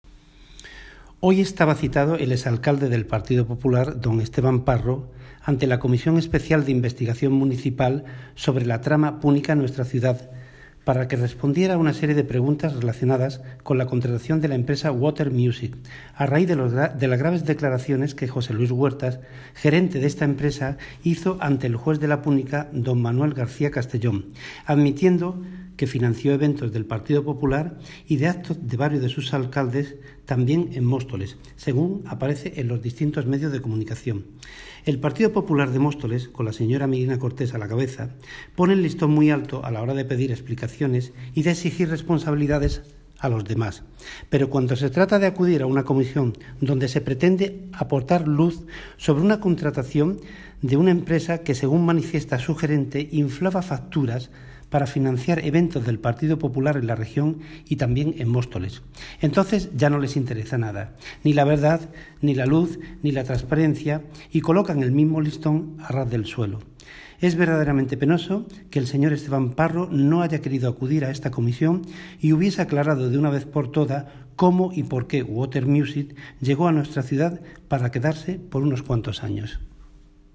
Audio - Agustín Martín (Concejal de Deportes, Obras, Infraestructuras y Mantenimiento de vías públicas)